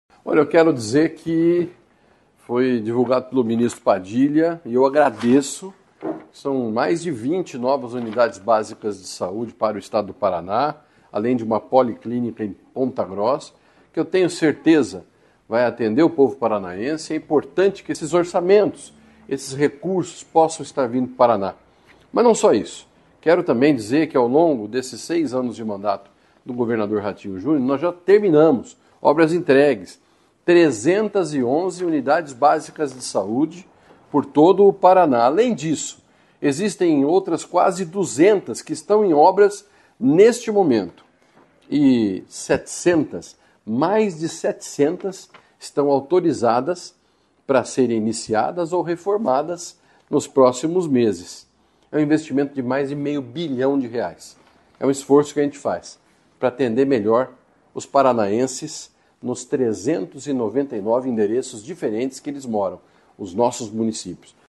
Sonora do secretário Estadual da Saúde, Beto Preto, sobre as UBS entregues, em obras ou em projeto no Estado